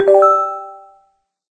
chime_2.ogg